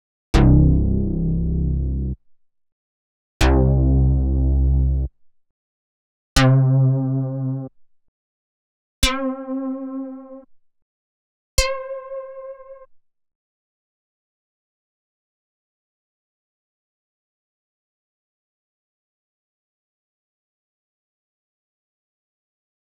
30_SuperJupiBass_C1-5.wav